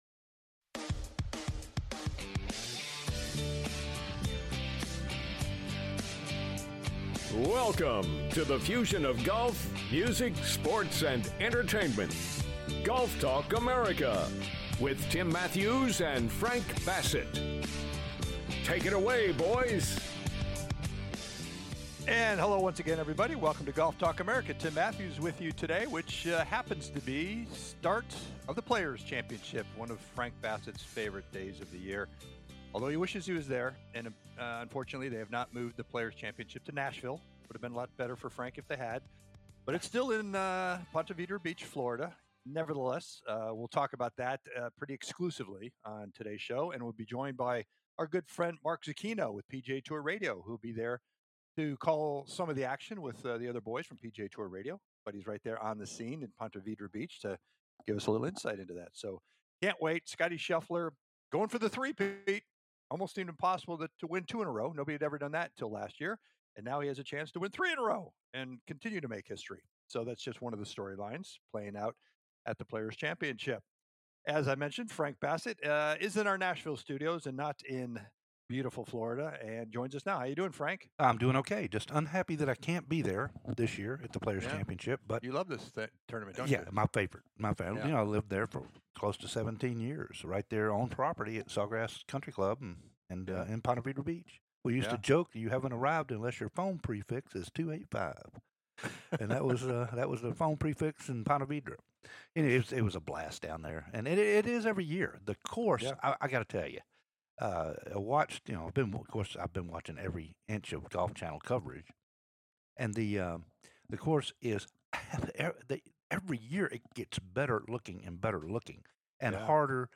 LIVE FROM PONTE VEDRA BEACH FOR THE PLAYERS CHAMPIONSHIP